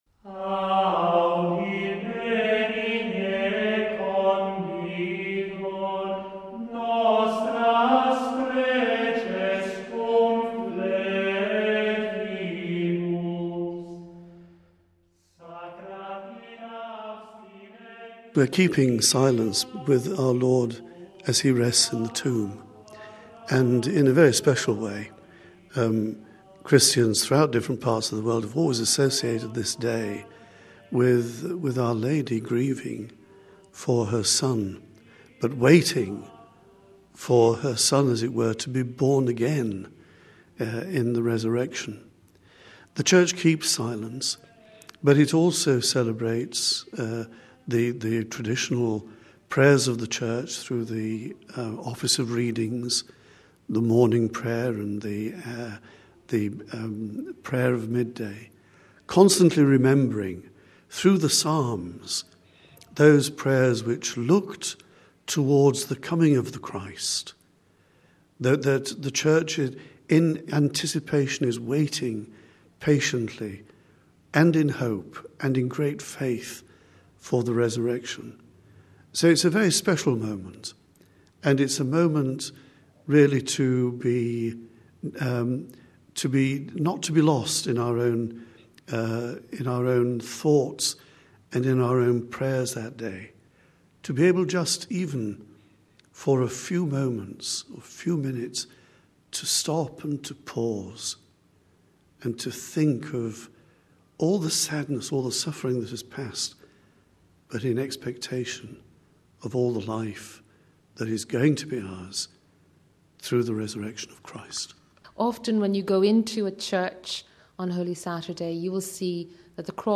(Vatican Radio) In a series of reflections, the Secretary of the Vatican Congregation for Divine Worship and Discipline of the Sacraments, Archbishop Arthur Roche, walks Vatican Radio through the Holy Week liturgies, explaining their significance, symbolism and place within the history of Christ’s Passion, Death and Resurrection.